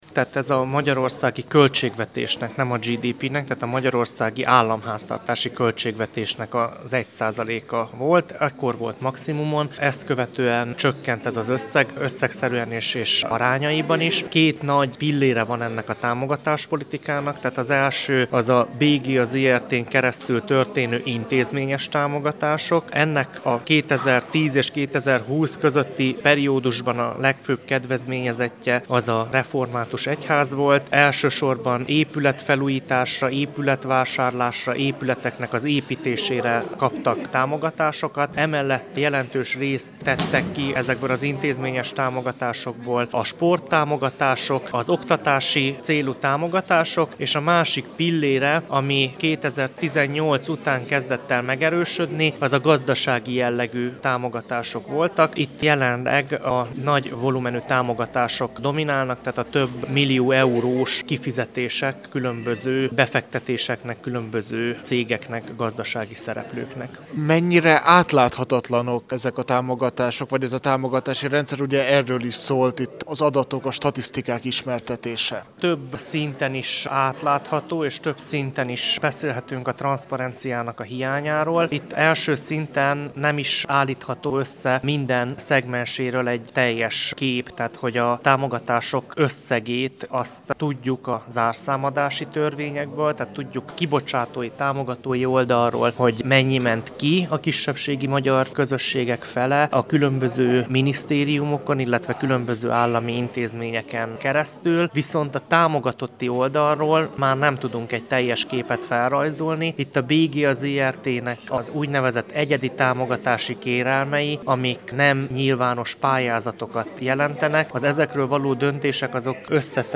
A csíkszeredai esemény után beszélgettünk az előadókkal.